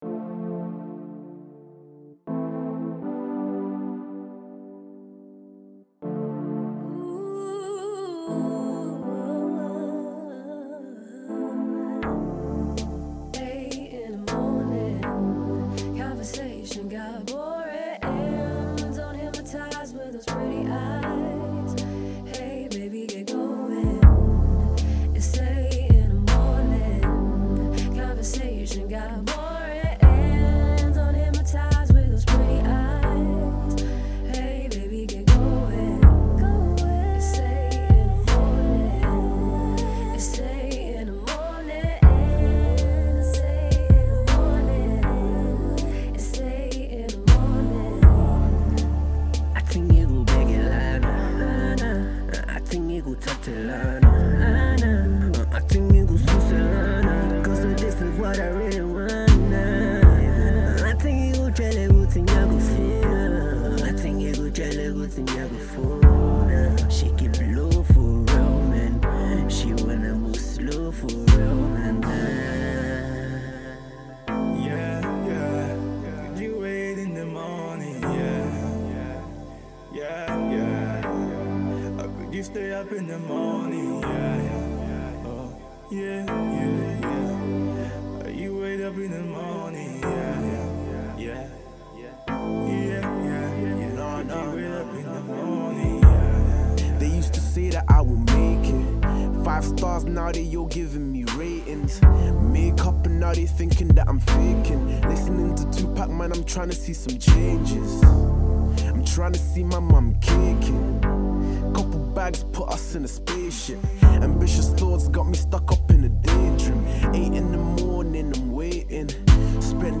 scorching and smooth song